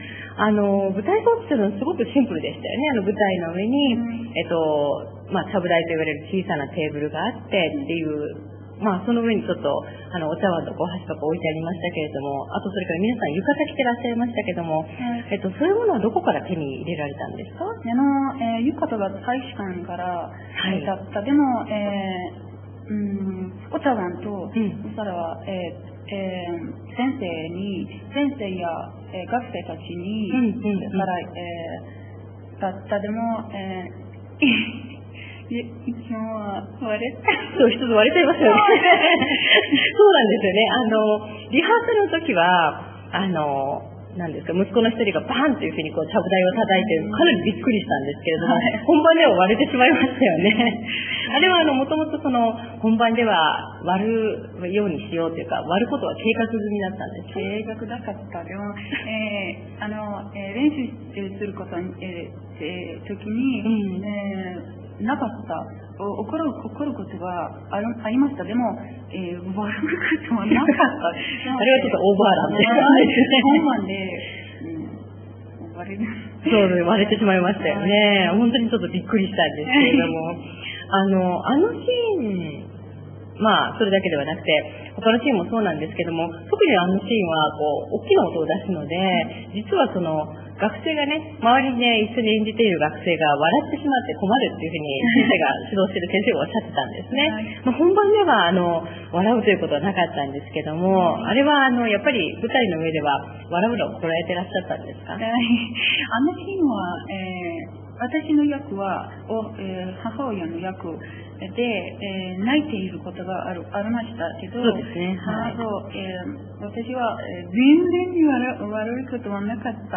テヘラン大学外国語学部4年生へのインタビュー（２）